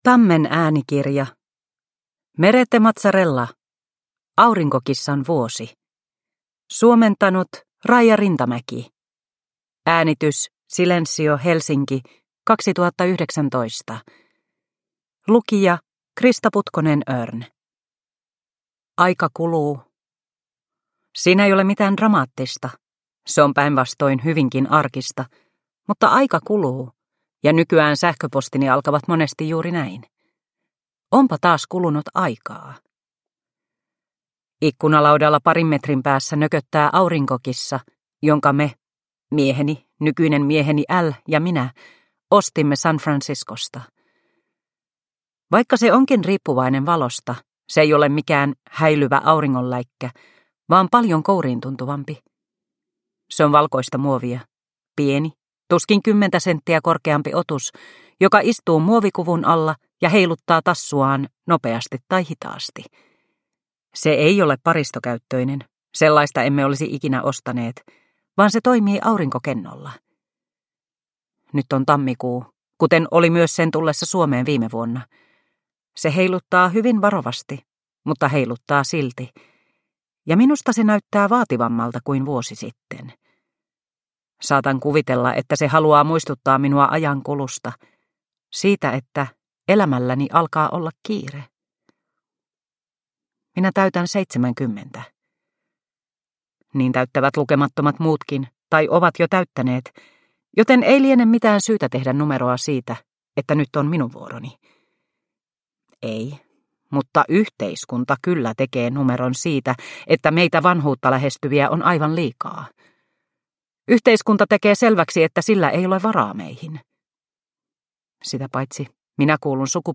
Aurinkokissan vuosi – Ljudbok – Laddas ner